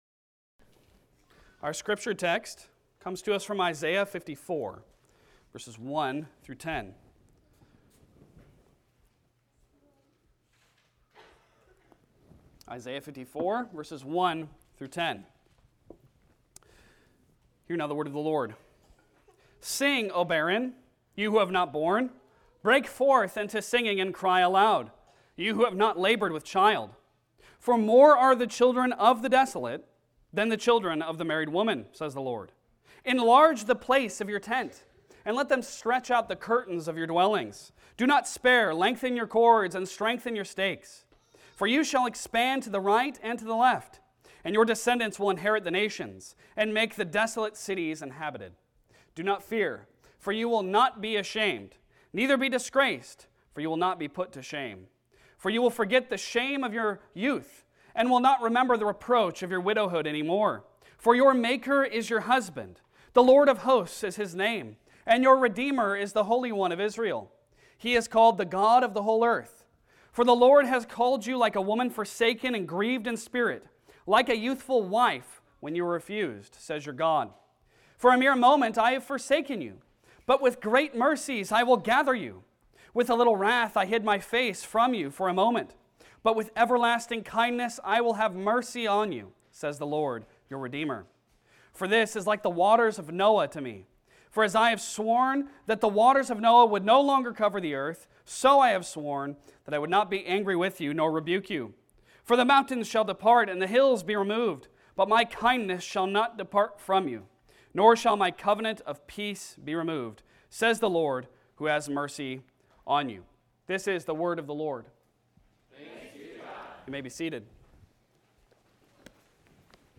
Passage: Isaiah 54:1-10 Service Type: Sunday Sermon